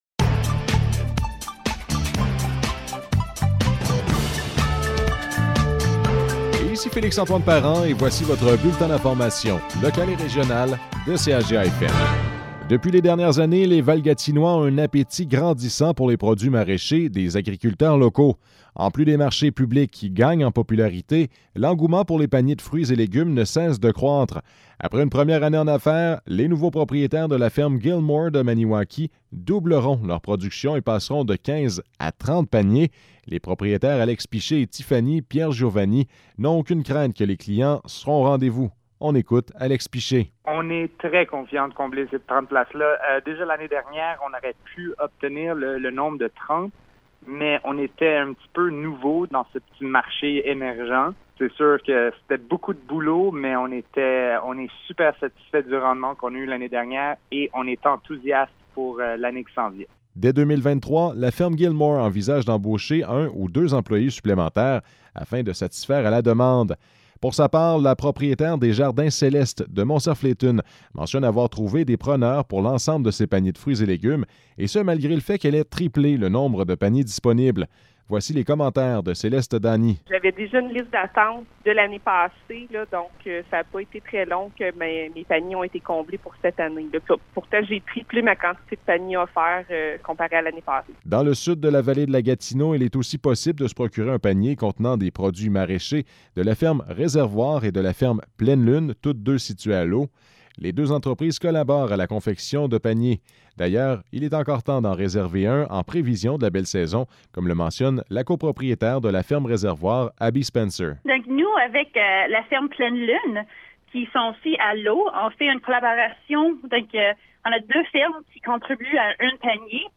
Nouvelles locales - 11 mars 2022 - 12 h